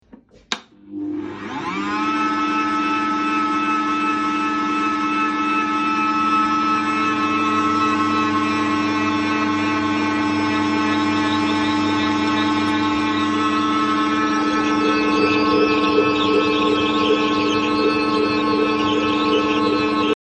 Звуки мясорубки
Включение и шум электромясорубки